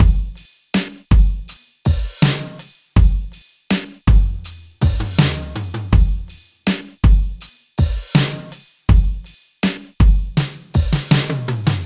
随机的" 水流激荡的隆隆声工业噪音工厂和火车我想
描述：水急流低温隆隆工业噪音工厂分机和火车我认为.flac
标签： 湍急 水分多 隆隆声
声道立体声